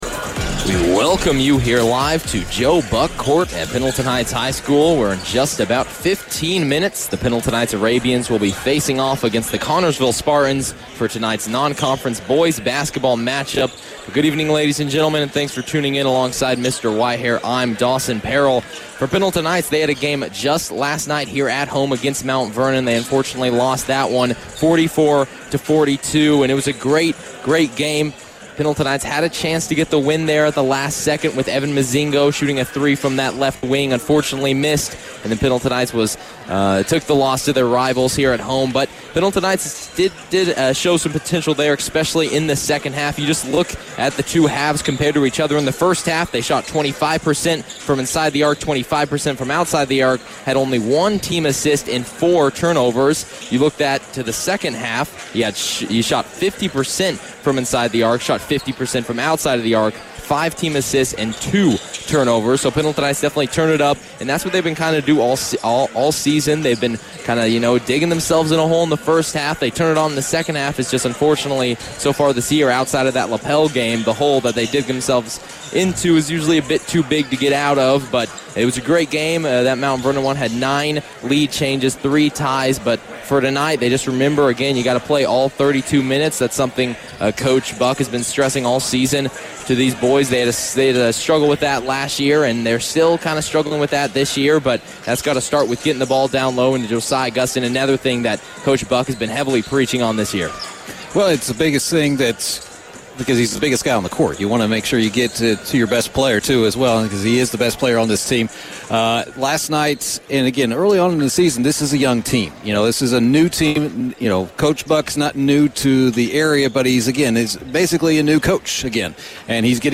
Varsity Boys Basketball Broadcast Replay Pendleton Heights vs. Connersville 12-14-23